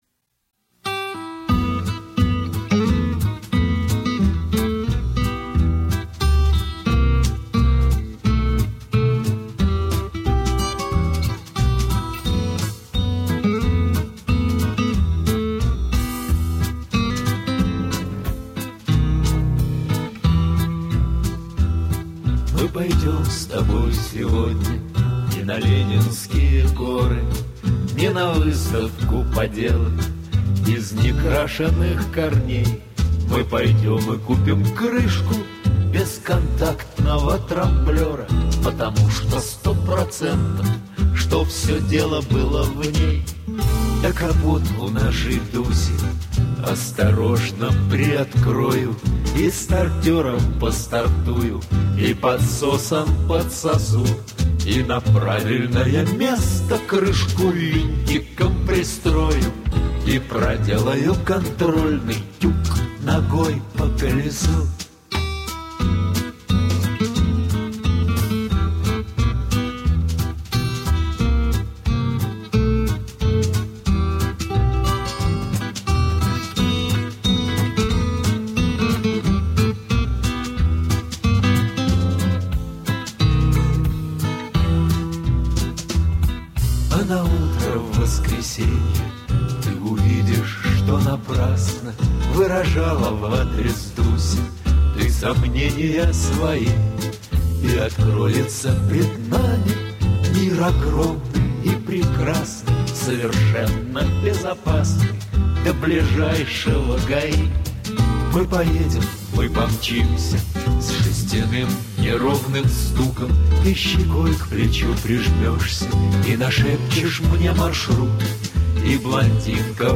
но песенка очень веселенькая и жизнеутверждающая smile